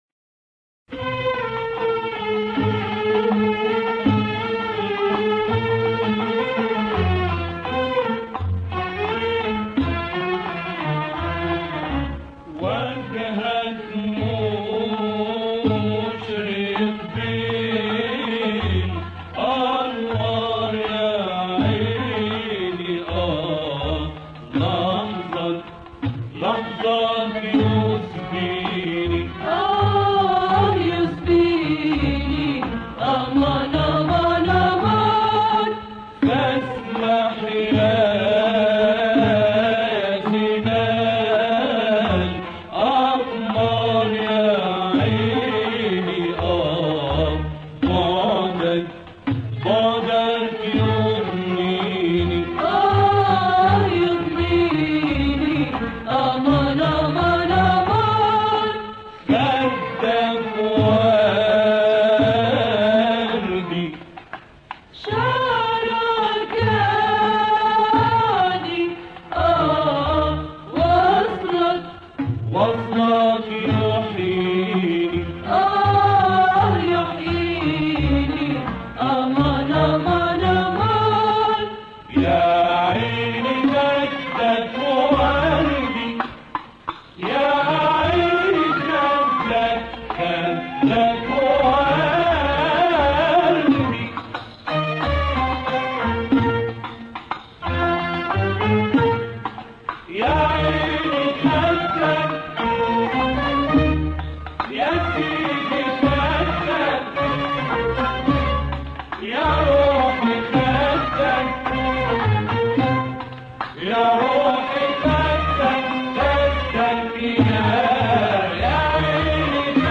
Two Muwashahat